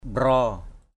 /ɓrɔ:/
mbraow.mp3